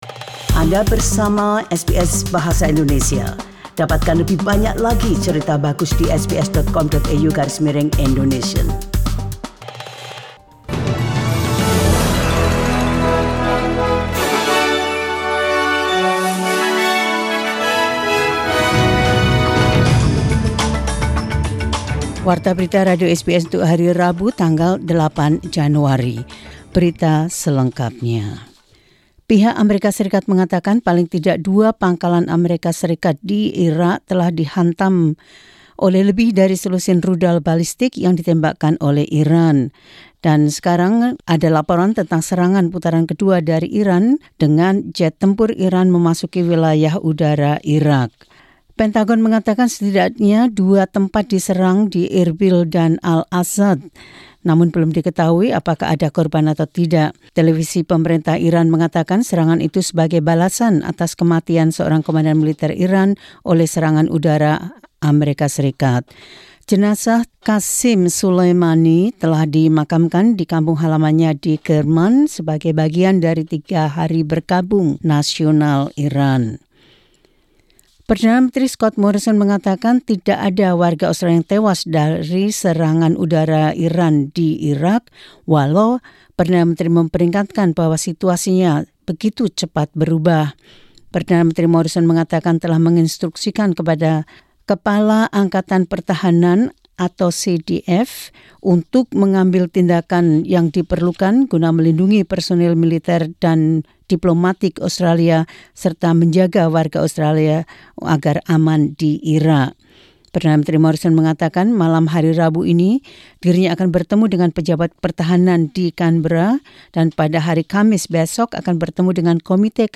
SBS Radio News in Indonesian 08 Jan 2020.